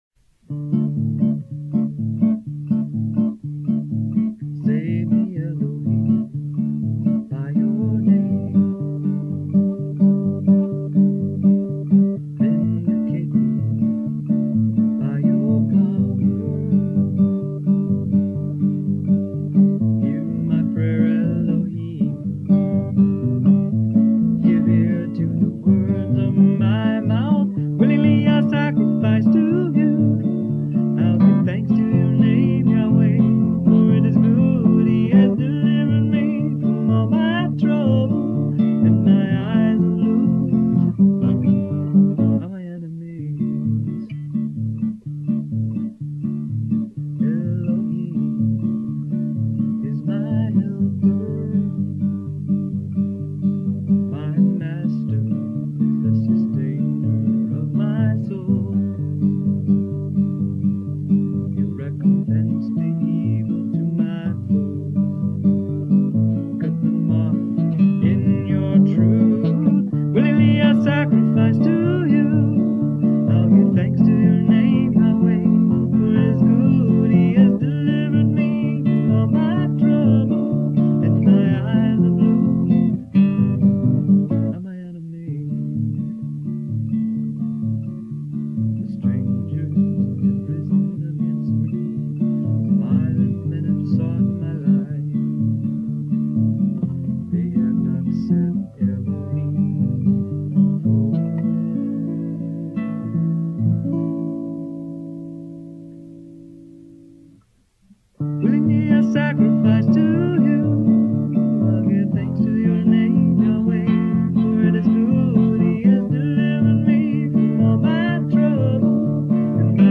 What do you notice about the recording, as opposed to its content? not the best recording but great music.